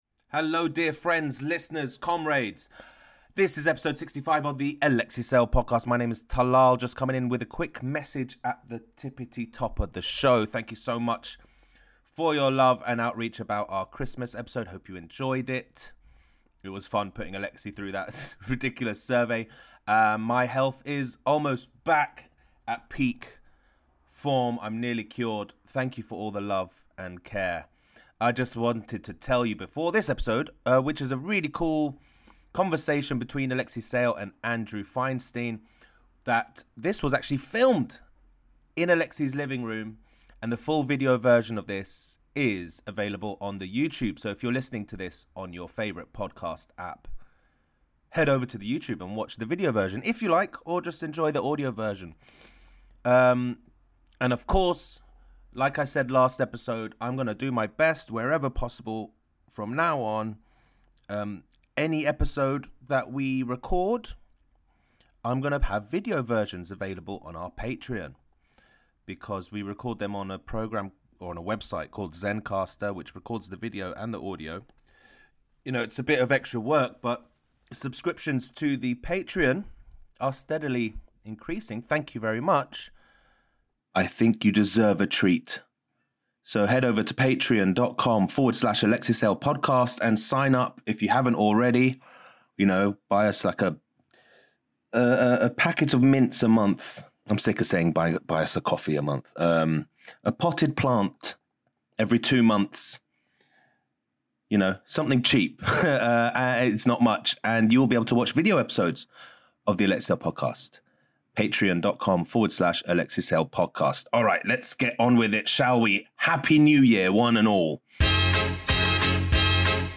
If you listen to The Alexei Sayle podcast you will not be disappointed, unless you are expecting something other than an old man talking crap.